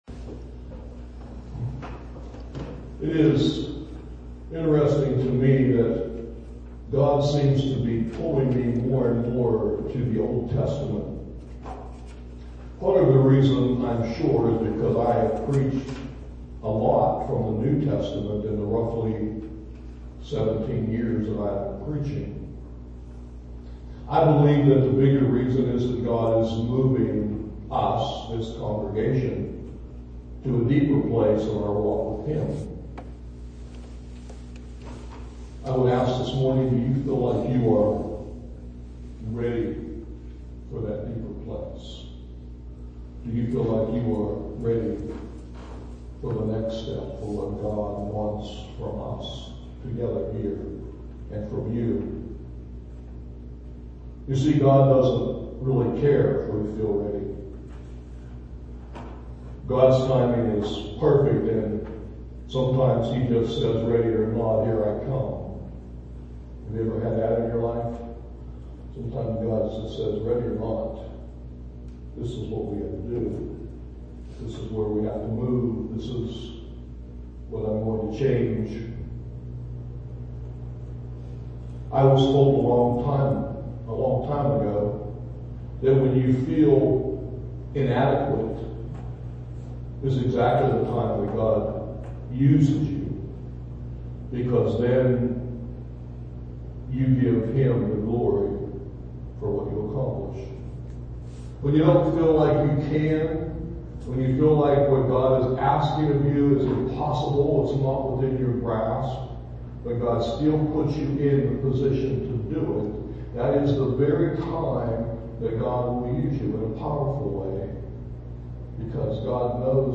MORNING MESSAGE TEXTS: Deuteronomy 30:11-20 and 1 Corinthians 3:1-9